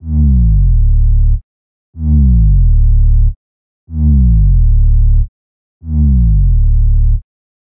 标签： 124 bpm Dance Loops Synth Loops 691.34 KB wav Key : A
声道立体声